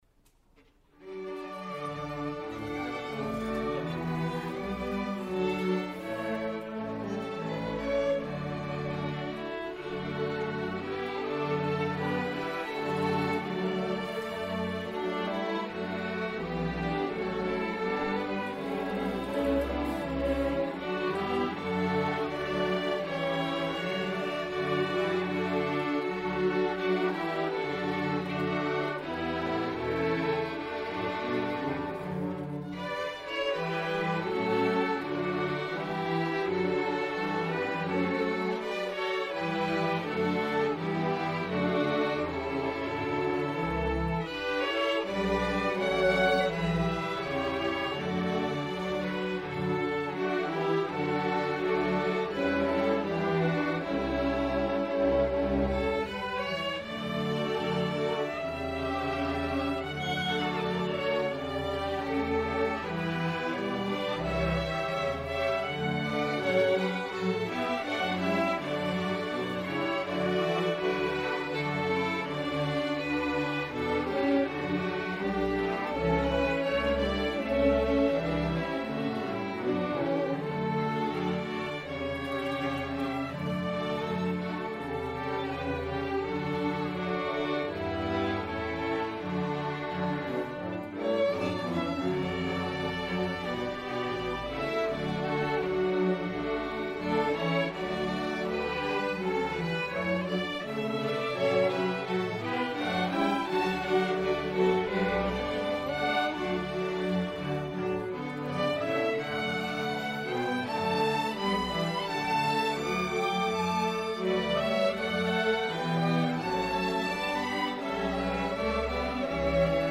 Queen City Community Orchestra
Fall 2022 Concert